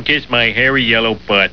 Auf dieser Seite findet ihr einige Soundsamples mit Sprüchen und Rumgedudel von Homer Simpson himself, natürlich ungekürzt und original auf englisch!